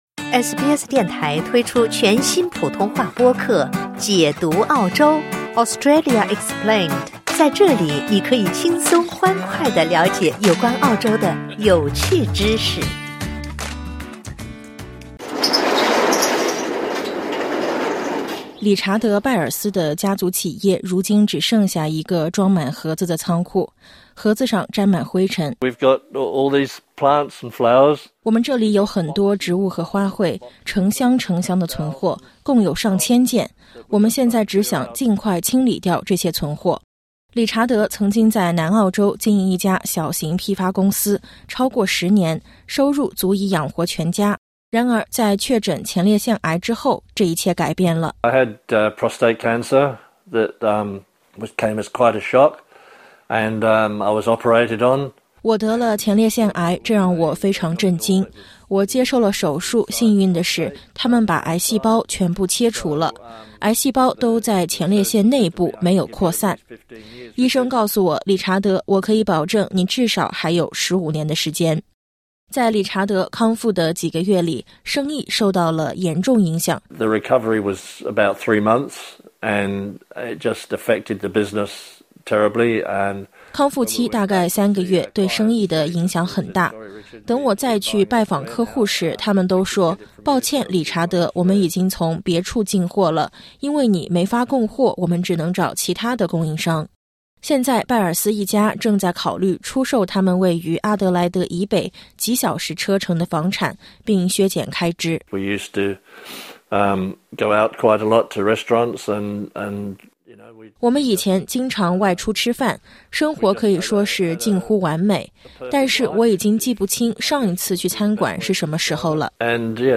根据食品救济慈善机构食物周济库（Foodbank）的报告，澳大利亚近一半的低收入家庭不得不跳过正餐，或在营养方面做出妥协。单亲家庭和偏远地区的家庭是最脆弱的群体，许多人被迫做出艰难的选择。点击音频，收听完整报道。